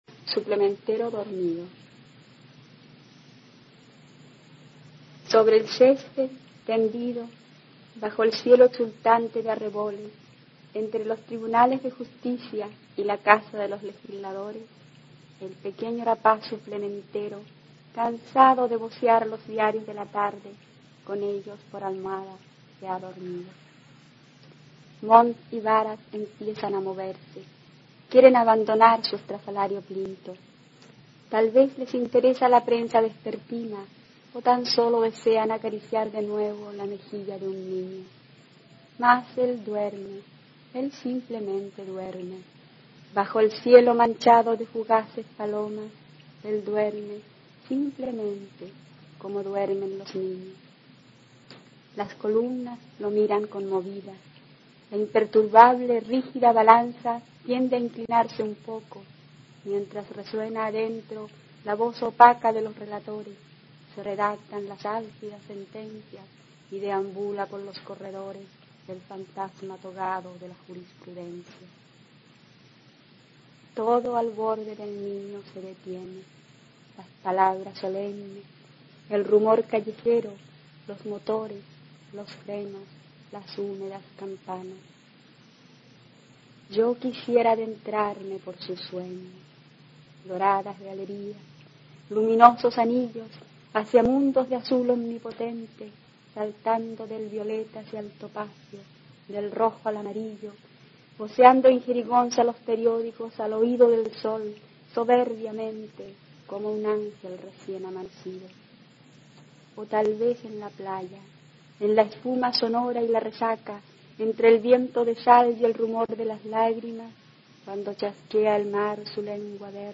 recitando su poema Suplementero dormido
Poesía
Poema